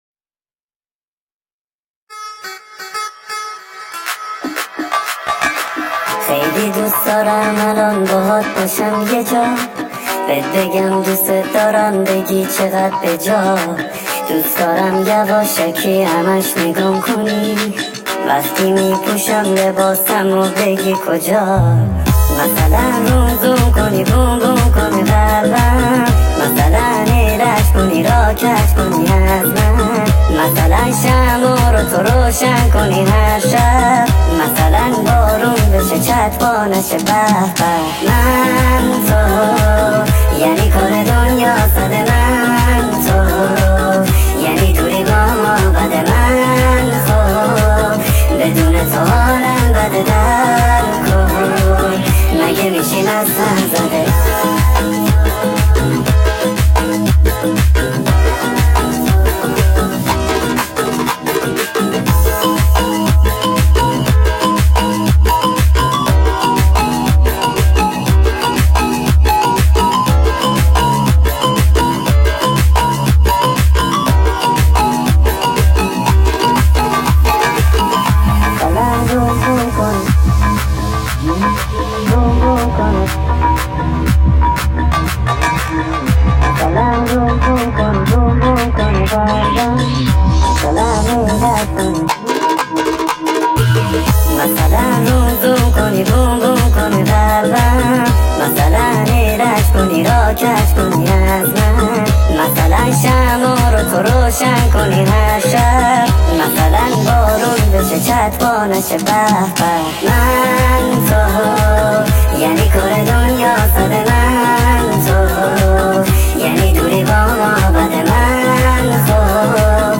با صدای بچه بچگانه ریمیکس